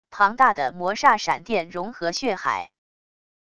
庞大的魔煞闪电融合血海wav音频